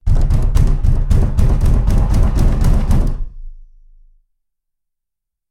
doorpound.ogg